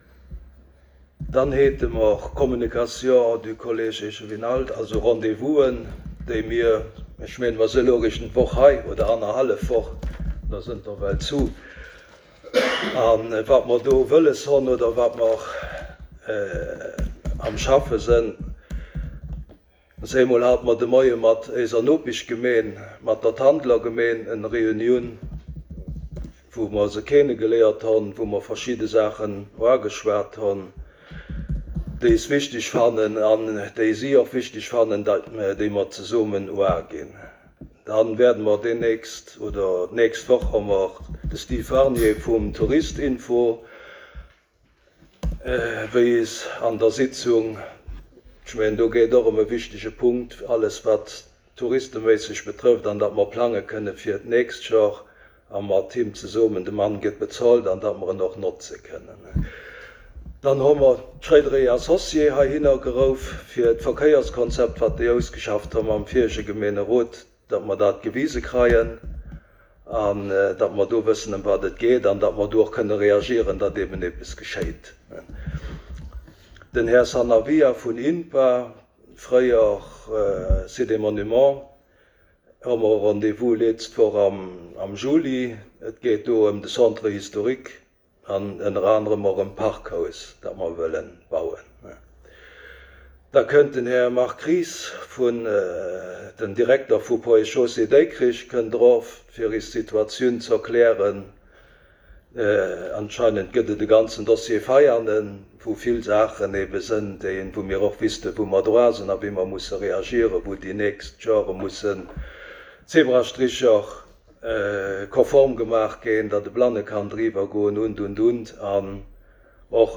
Conseil Communal du mercredi,19 juillet 2023 à 14.00 heures en la salle Bessling du Centre Culturel Larei